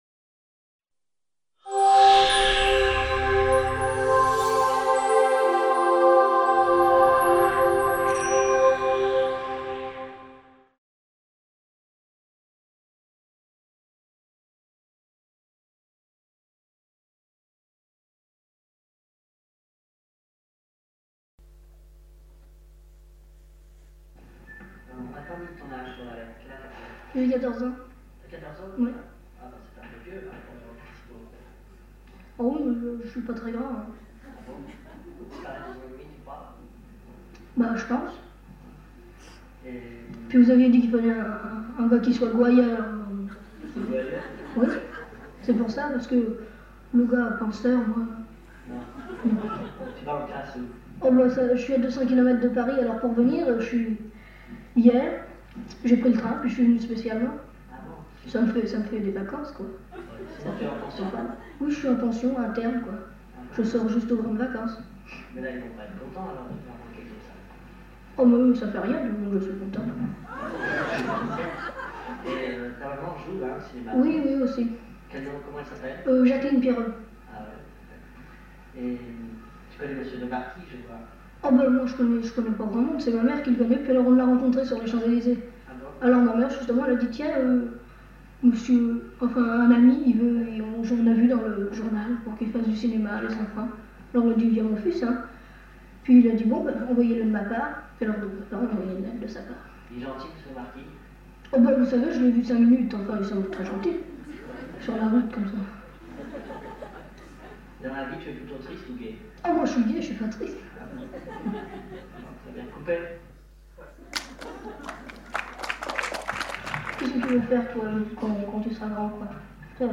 Quel français tu causes ? Les langues parlées dans notre cinéma. Table ronde | Canal U